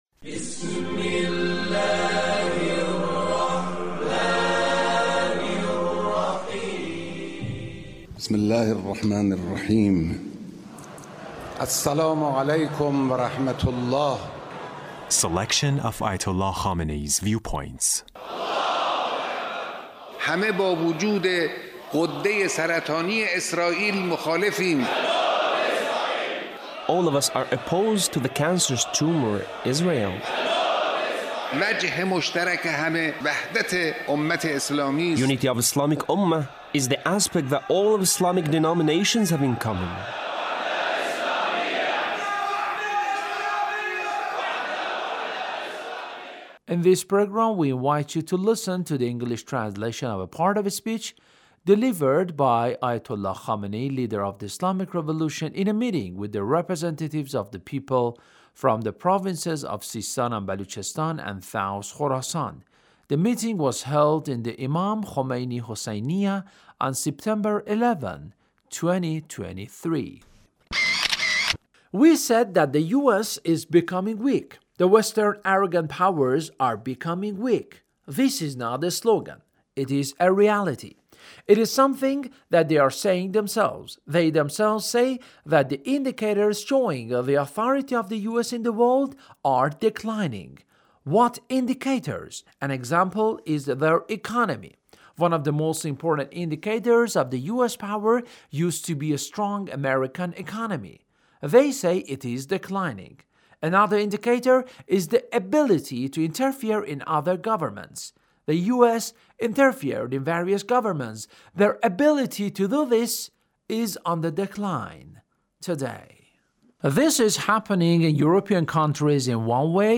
Leader's Speech (1835)
Leader's Speech with the people of SISTAAN